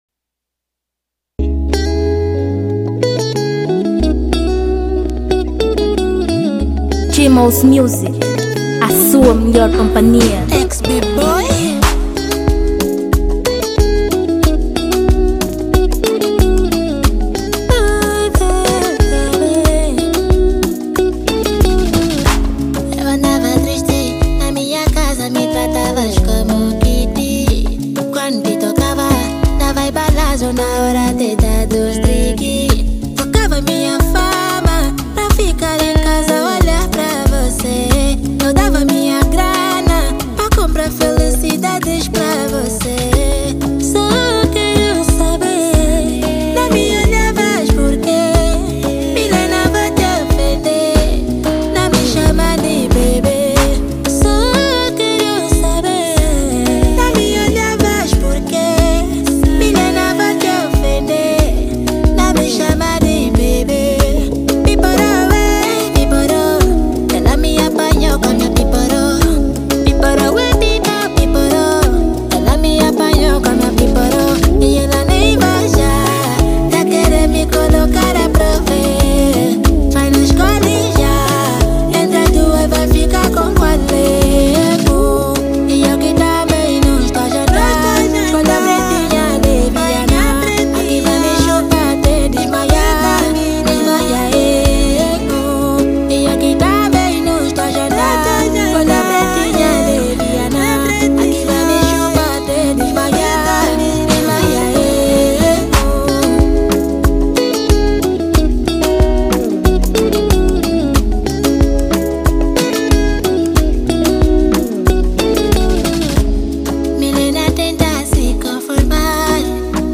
2023 Gênero: Zouk Tamanho